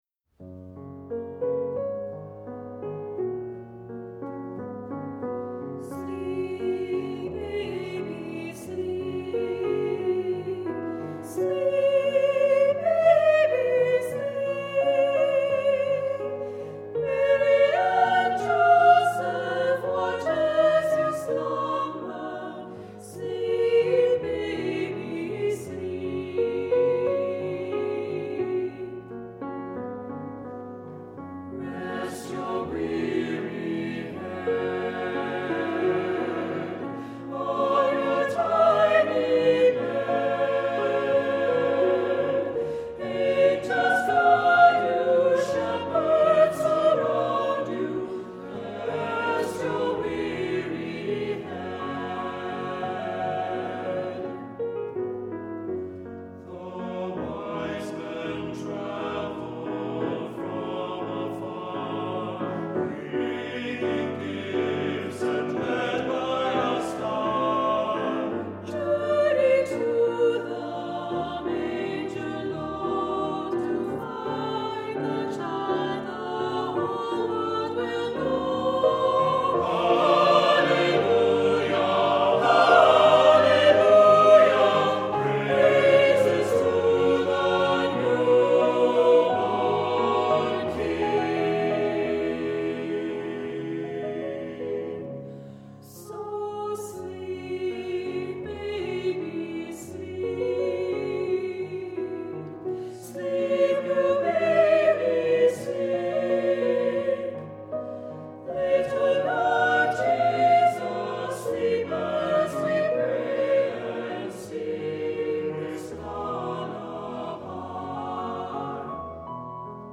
Accompaniment:      With Piano
Music Category:      Christian